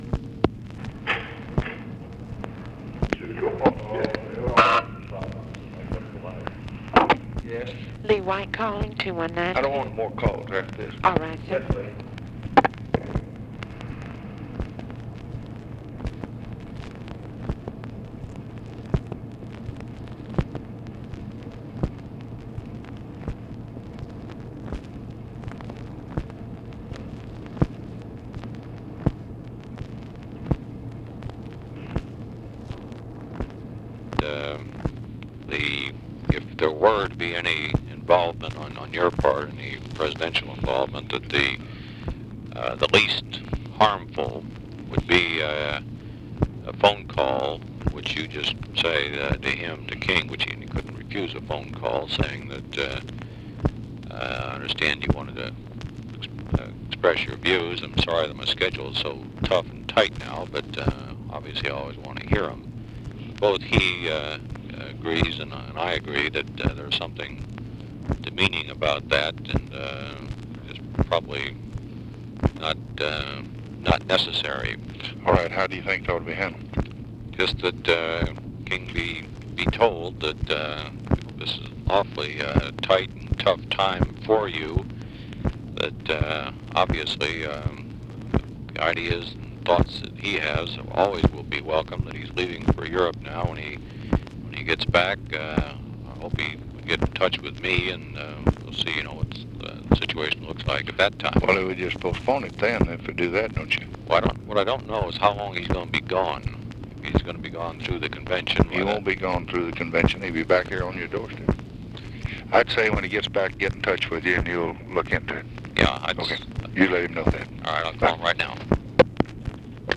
Conversation with LEE WHITE, August 13, 1964
Secret White House Tapes